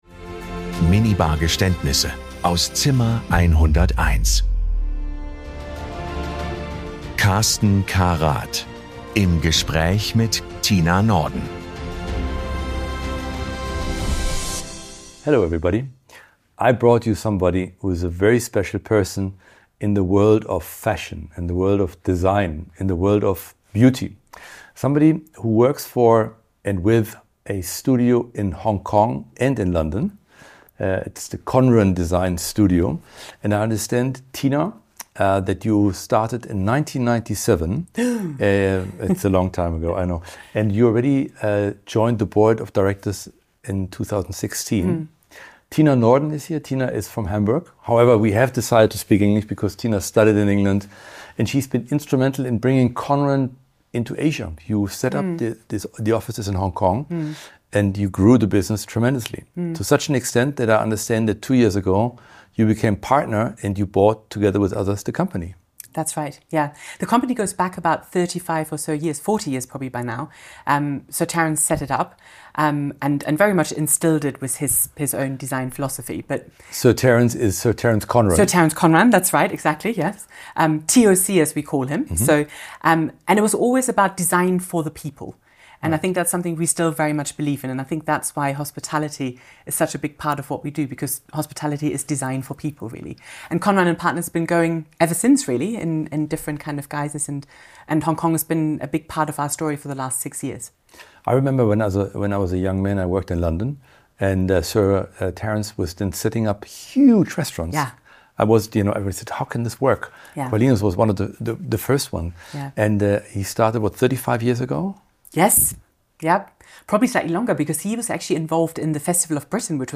Es wird gelacht, reflektiert und manchmal auch gestanden. Hier wird Hospitality persönlich.